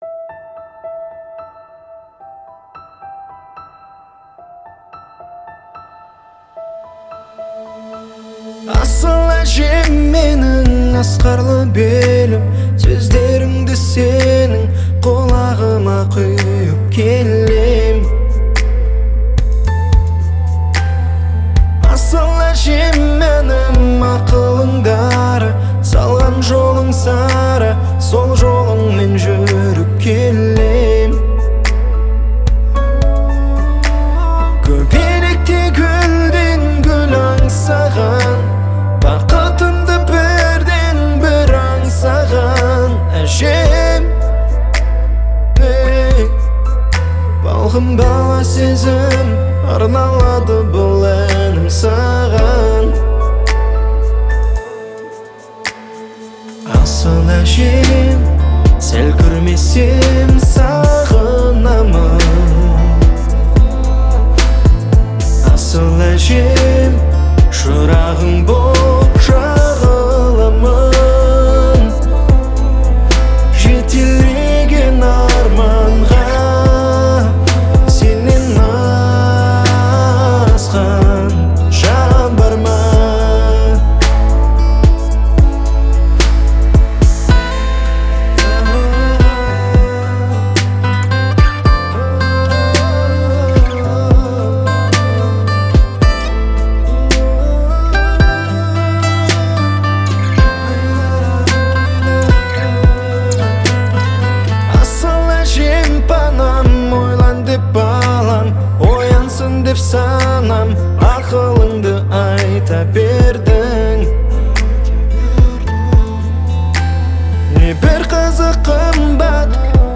это завораживающая композиция в жанре поп с элементами фолка